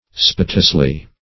spitously - definition of spitously - synonyms, pronunciation, spelling from Free Dictionary Search Result for " spitously" : The Collaborative International Dictionary of English v.0.48: Spitously \Spit"ous*ly\, adv.